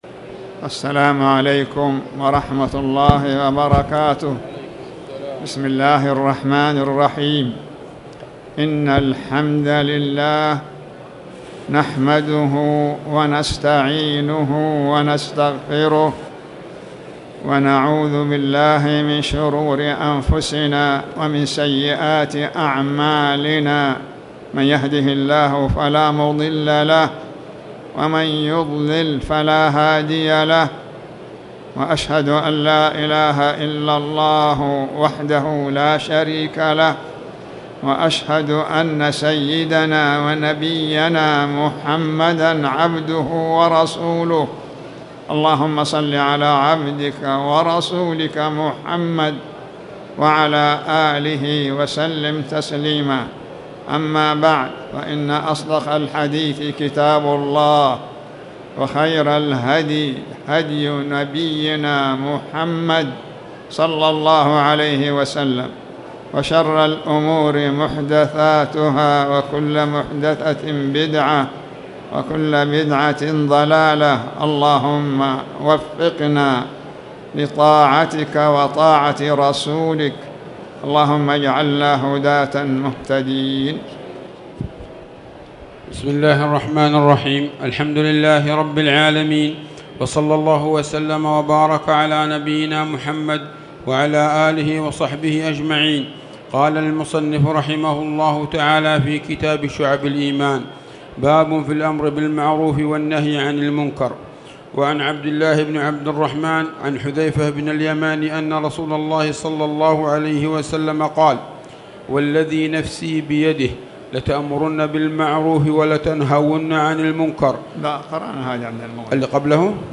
تاريخ النشر ٢٠ جمادى الآخرة ١٤٣٨ هـ المكان: المسجد الحرام الشيخ